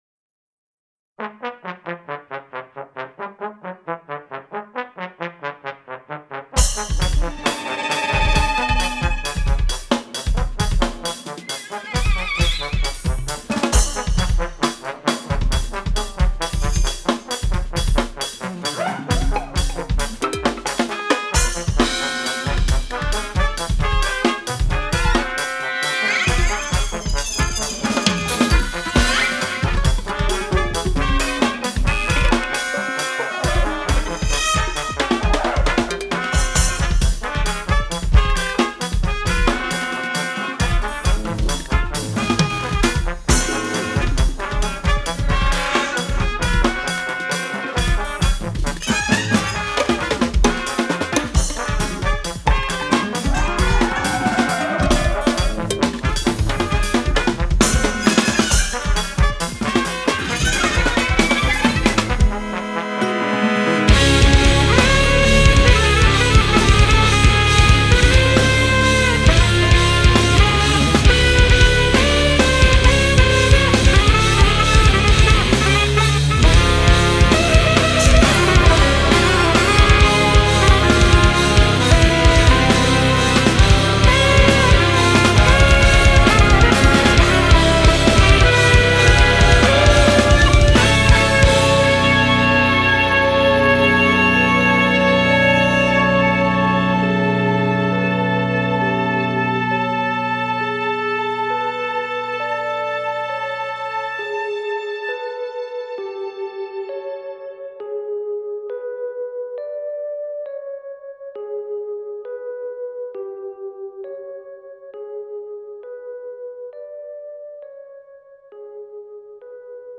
brutal polyrhytmic